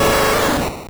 Cri de Tauros dans Pokémon Or et Argent.